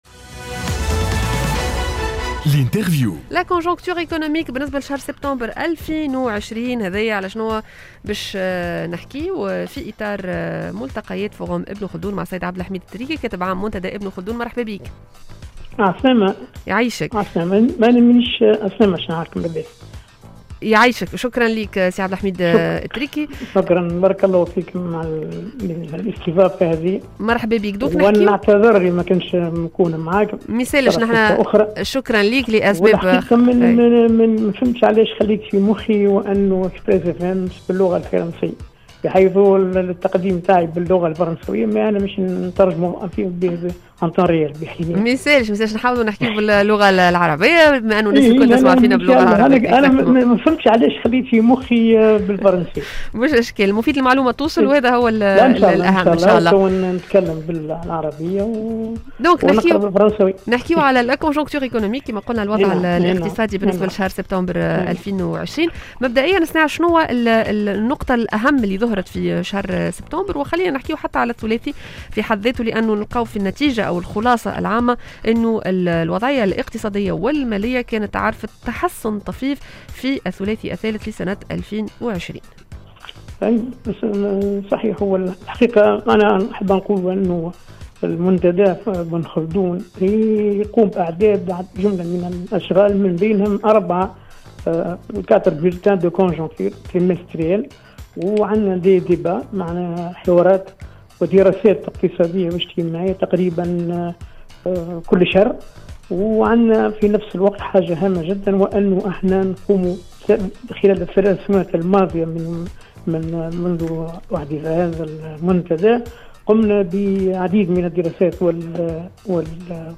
L'interview